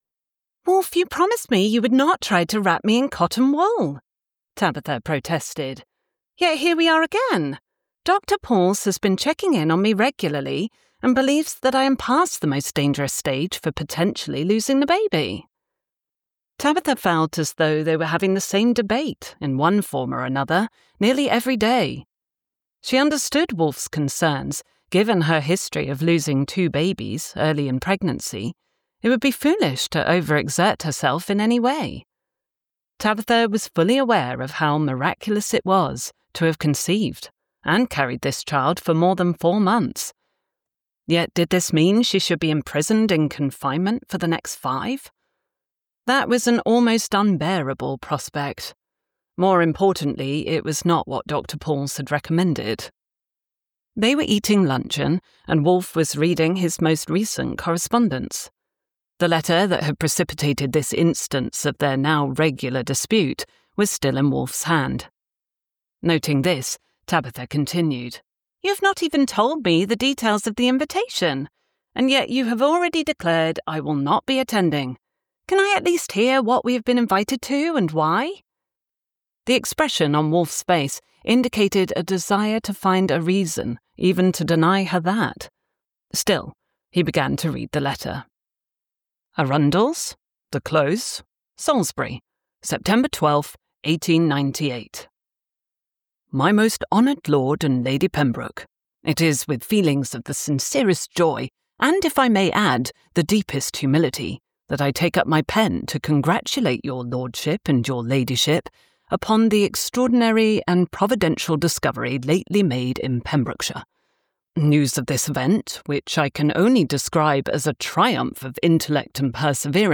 An Anointed Woman Audiobook